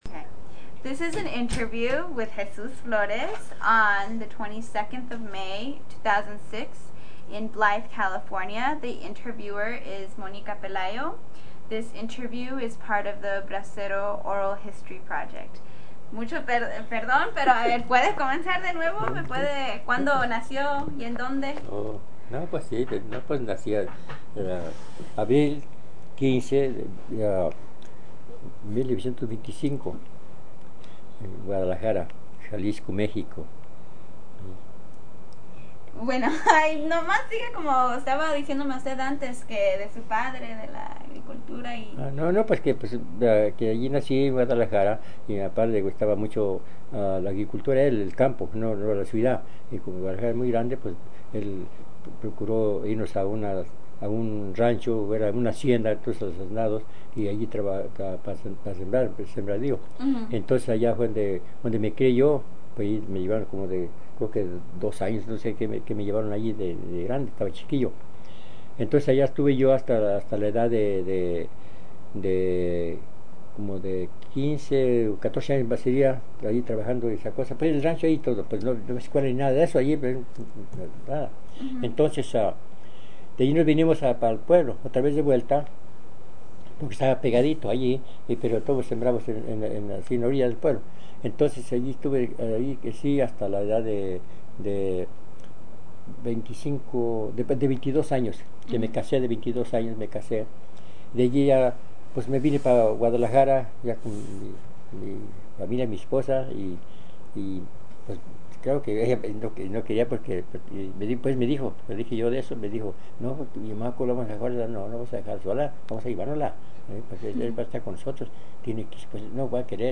Summary of Interview
Location Blythe, CA